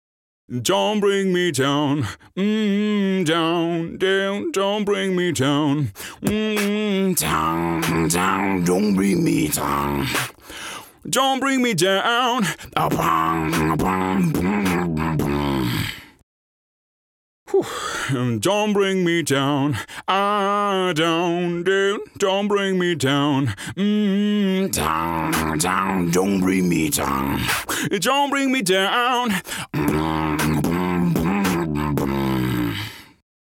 Tiefe Stimme
Sprechprobe: Sonstiges (Muttersprache):